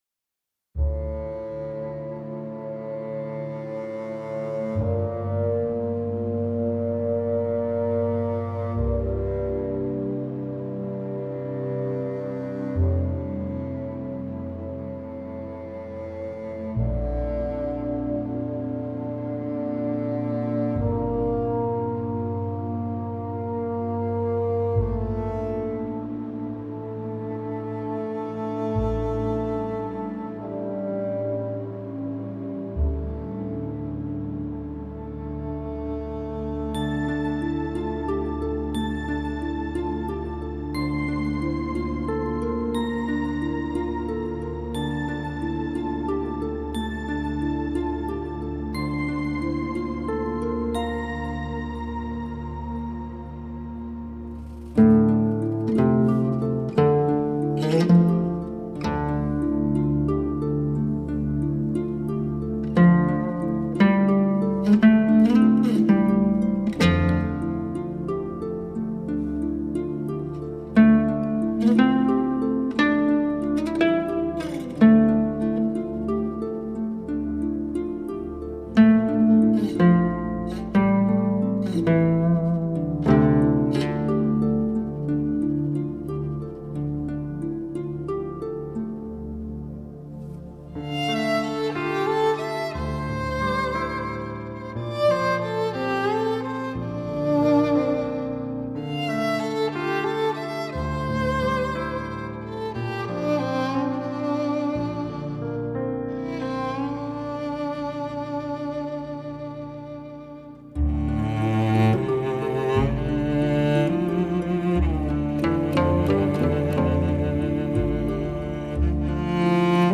感受西洋弦乐大提琴的醇厚魅力
丝音金声相融相错 明洌深邃 动容大千世界
阮与大提琴的对话
阮&大提琴
阮的声音，感觉有点像吉他，有时候还有点像鼓，有时候又有点像大提琴...